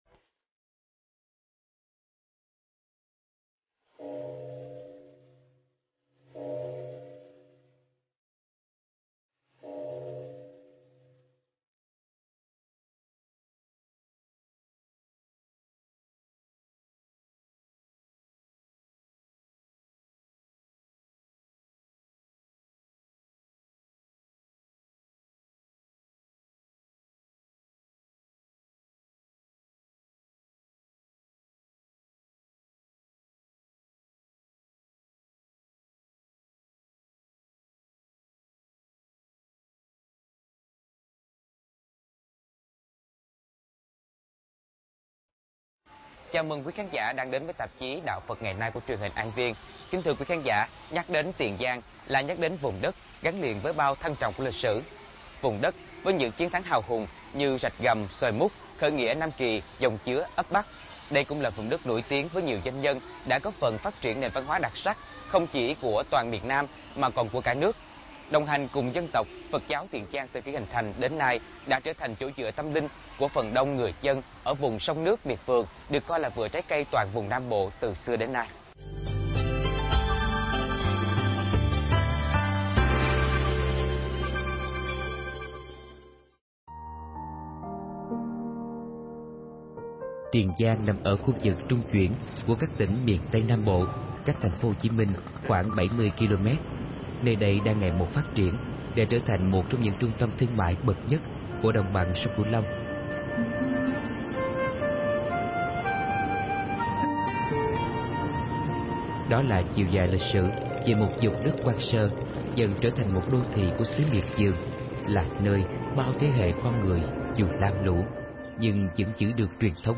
Pháp âm Phật giáo Tiền Giang: Miền đất thấm đẫm tình người - thầy Thích Nhật Từ